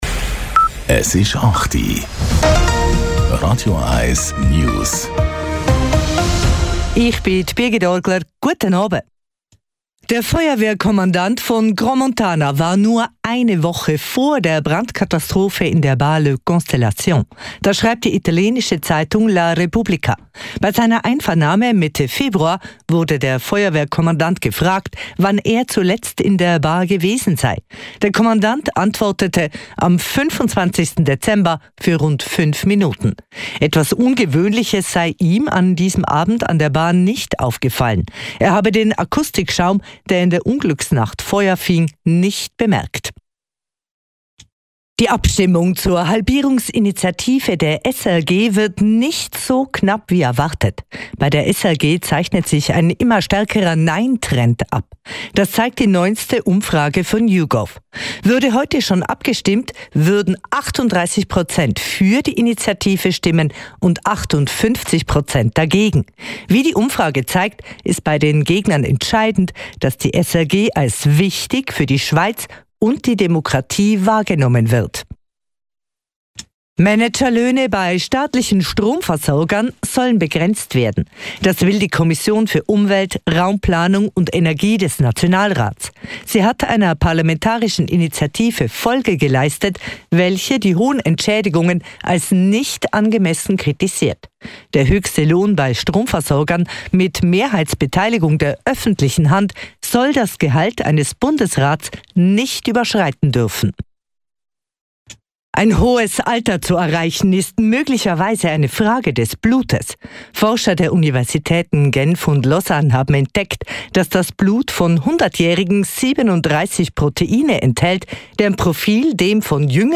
Nachrichten & Politik